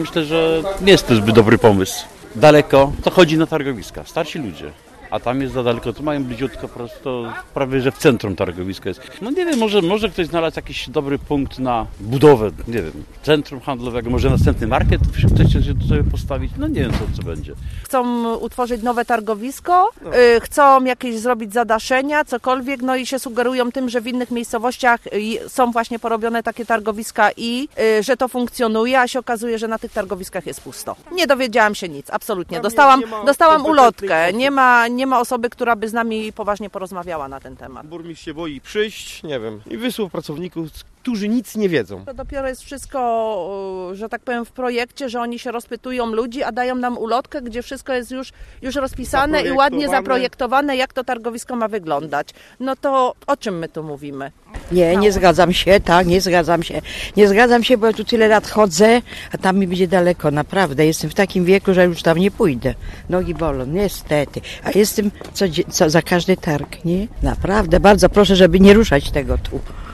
Nasza reporterka zapytała również  handlowców i klientów żnińskiego targowiska, co sądzą o nowym pomyśle oraz co ich zdaniem może powstać na Placu Zamkowym.